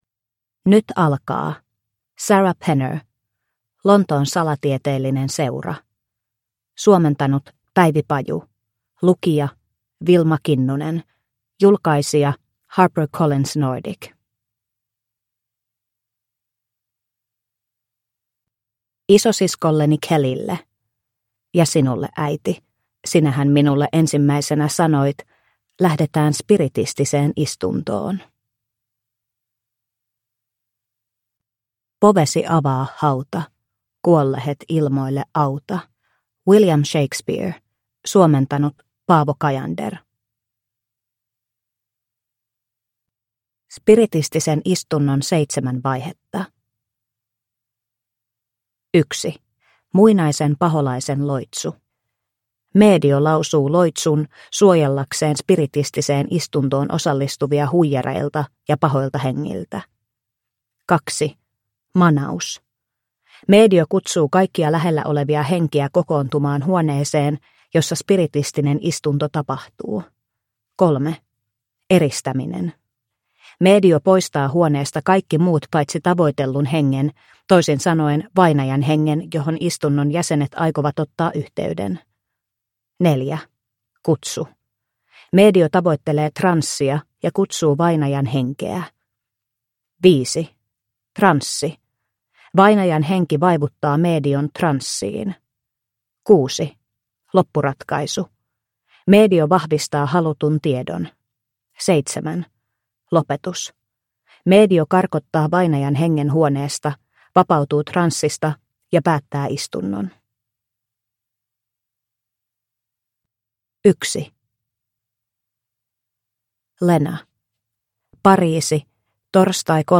Lontoon salatieteellinen seura – Ljudbok – Laddas ner